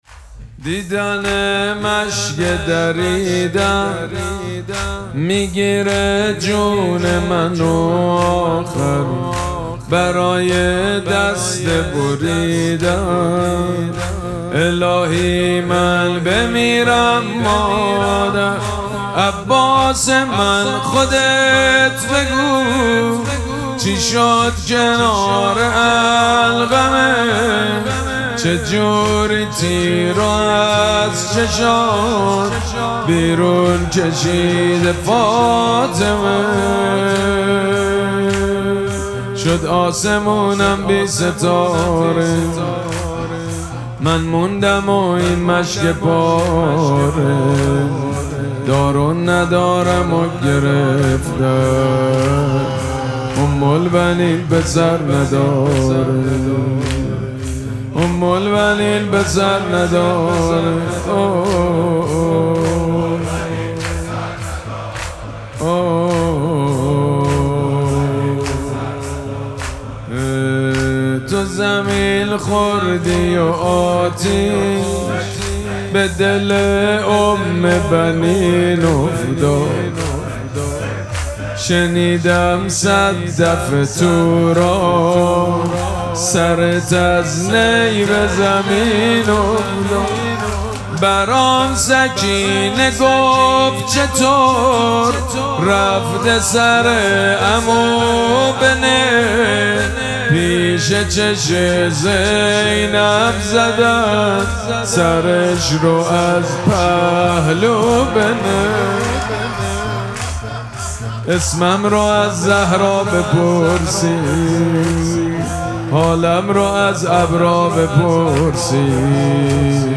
مراسم مناجات شب نهم ماه مبارک رمضان
حسینیه ریحانه الحسین سلام الله علیها
مداح
حاج سید مجید بنی فاطمه